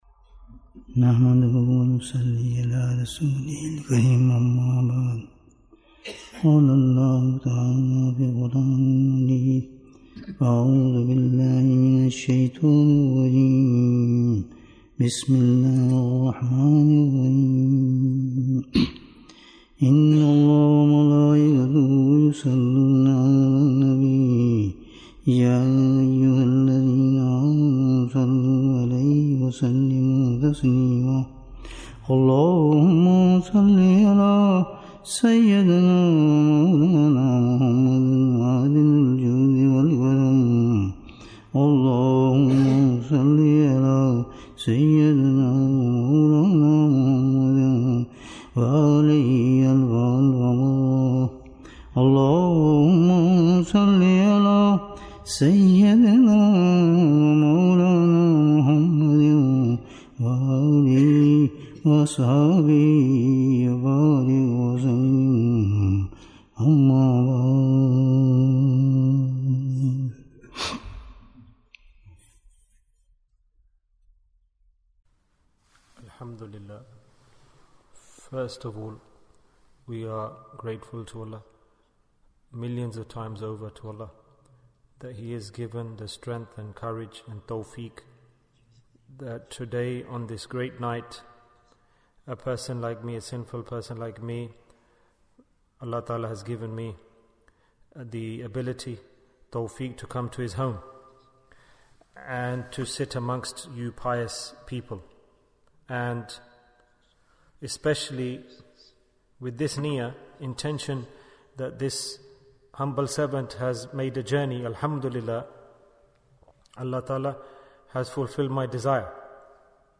Shab-e-Miraaj Bayan, 65 minutes18th February, 2023